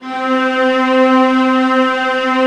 VIOLAS DN4-R.wav